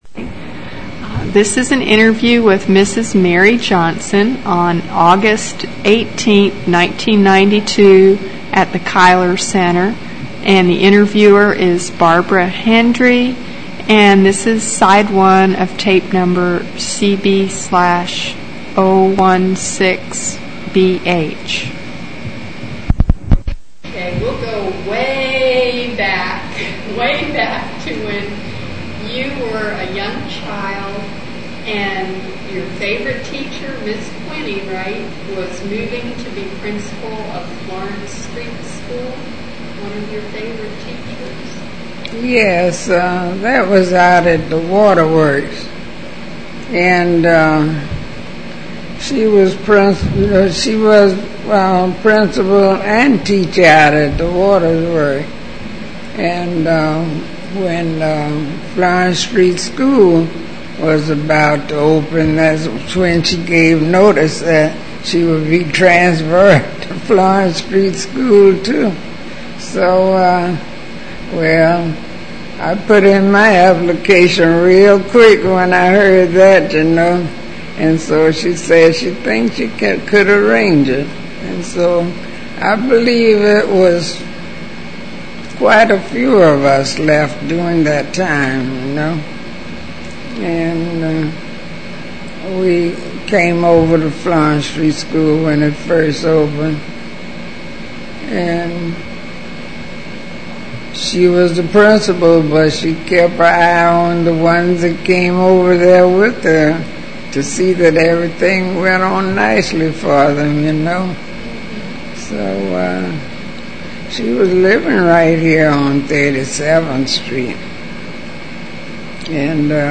Interview
Object Name Tape, Magentic Credit line Courtesy of City of Savannah Municipal Archives Copyright Requests to publish must be submitted in writing to Municipal Archives.